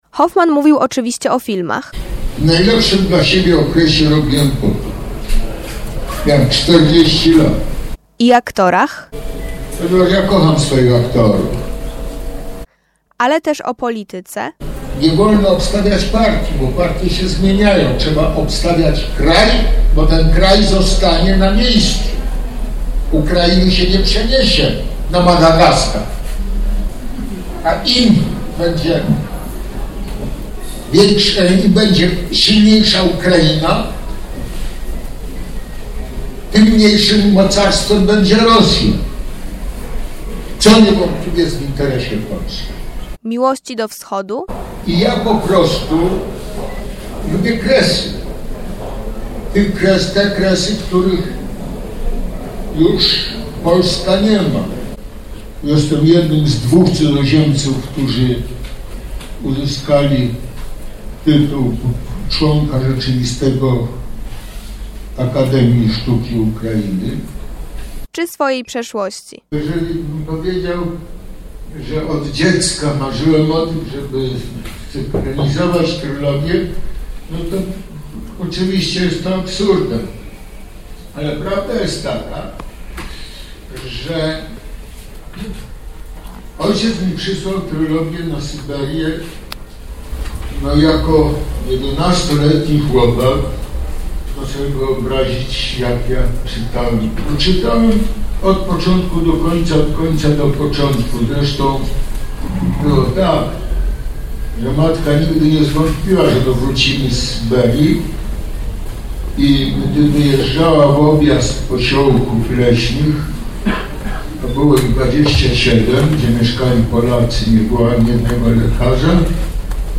Podczas festiwalu fani kina mogli spotkać się z tą żywą legendą. Hoffman mówił o filmach i aktorach – odniósł się do obelg, jakie swego czasu rzucano w stronę Olbrychskiego i wyjaśnił, skąd powszechna niechęć wobec Nataszy Urbańskiej. Wspominał swoje dzieciństwo, nie stronił też o komentarzy politycznych w kontekście konfliktu Rosji z Ukrainą.